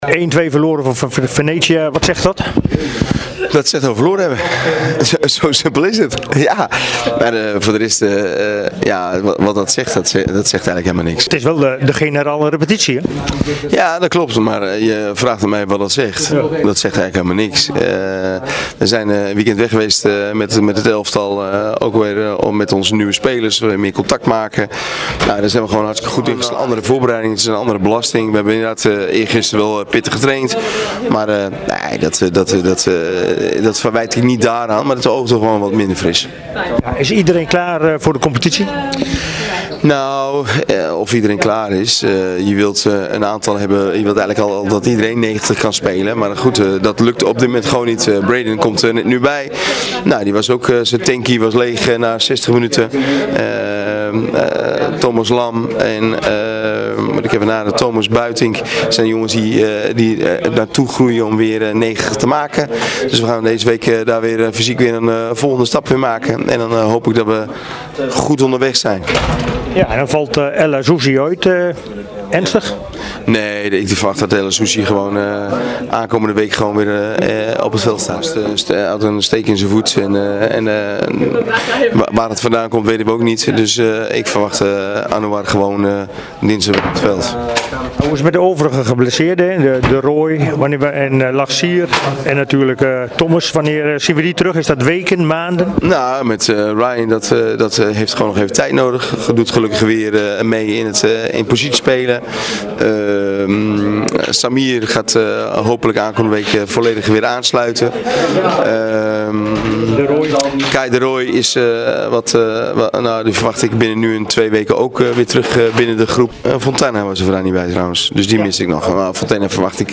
In een interview na de wedstrijd gaf hij aan dat de uitslag weinig zegt over de algehele voorbereiding van het team.